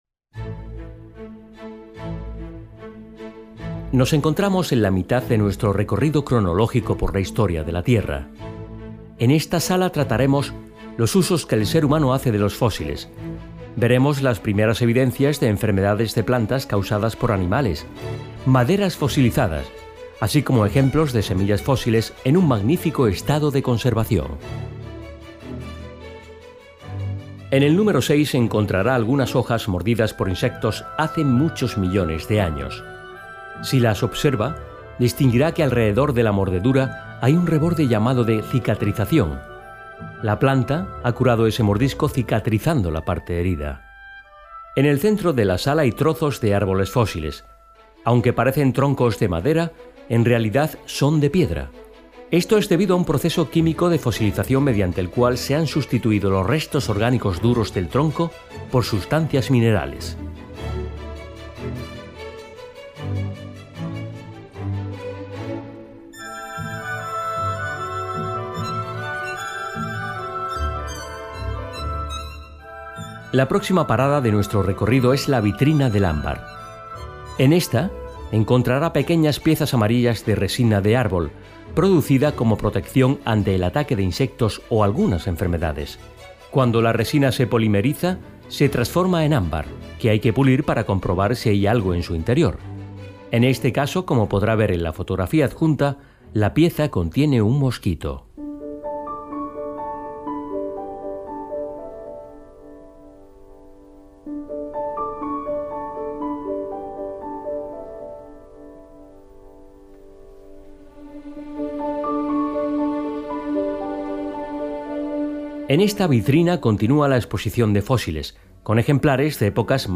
Planta-inferior-Museo-Paleobotánica.mp3 Consulte la audio guía de la sala superior del Museo.